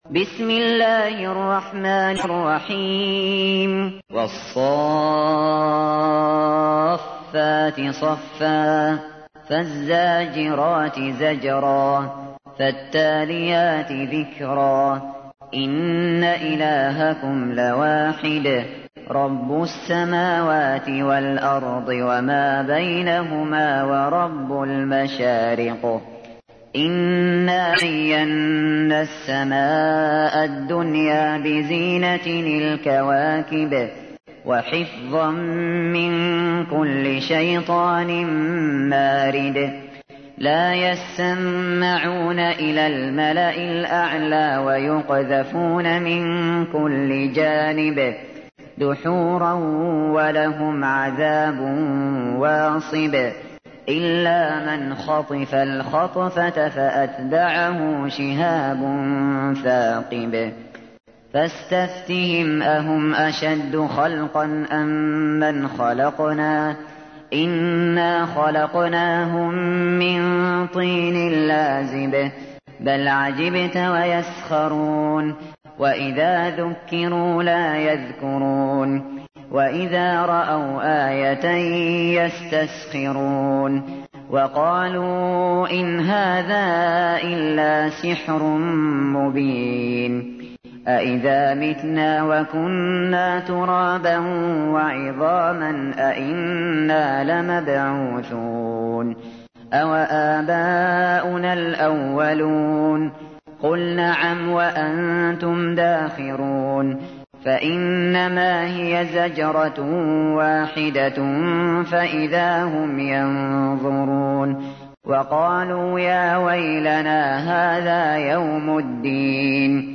تحميل : 37. سورة الصافات / القارئ الشاطري / القرآن الكريم / موقع يا حسين